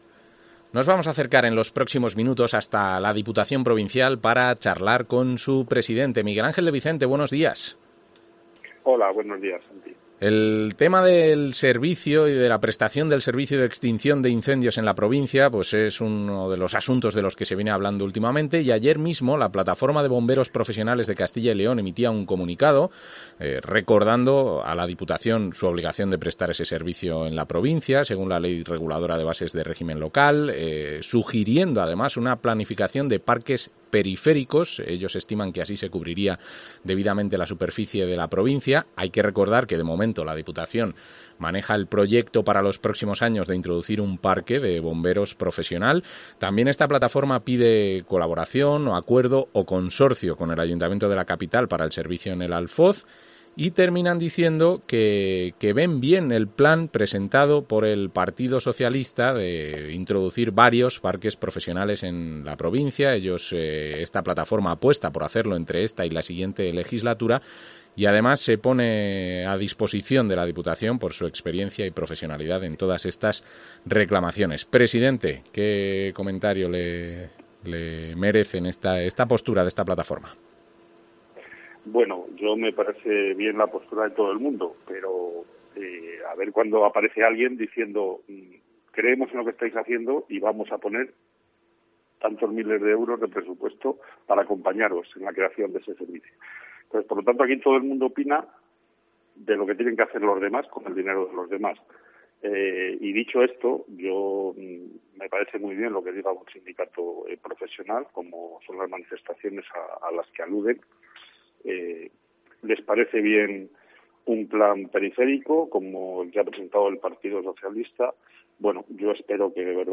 Entrevista al presidente de la Diputación de Segovia, Miguel Ángel de Vicente